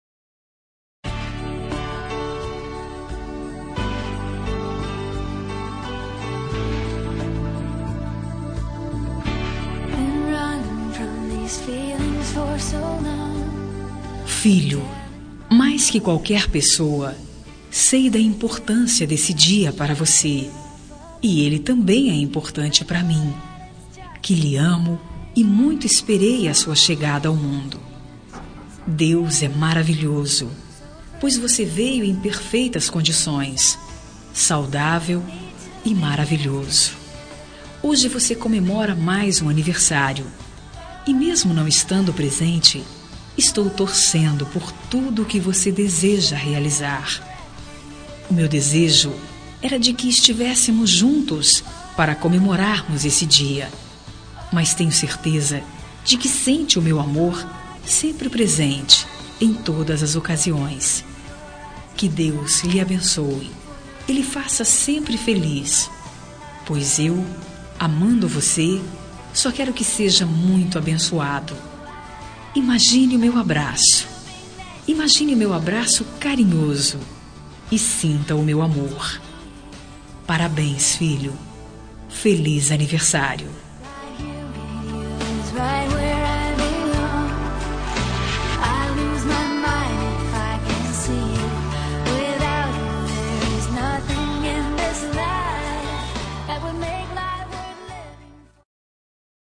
Telemensagem de Aniversário de Filho – Voz Feminina – Cód: 1829